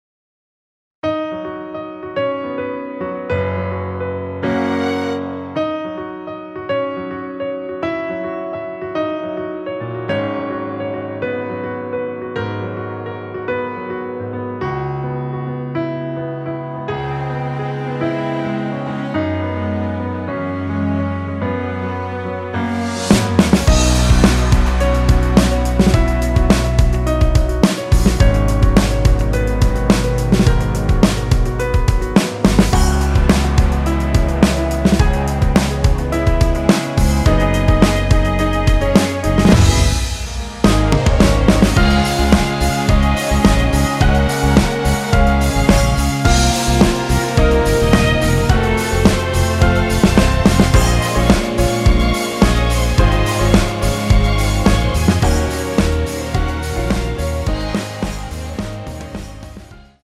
전주 없이 시작 하는곡이라 노래 하시기 좋게 2마디 전주 만들어 놓았습니다.(약 5초쯤 노래 시작)
◈ 곡명 옆 (-1)은 반음 내림, (+1)은 반음 올림 입니다.
앞부분30초, 뒷부분30초씩 편집해서 올려 드리고 있습니다.